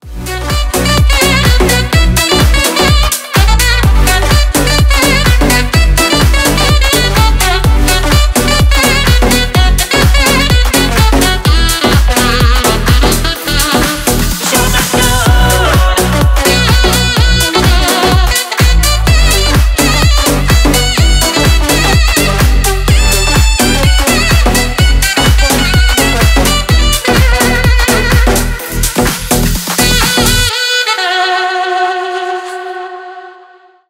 Поп Музыка
кавер
ритмичные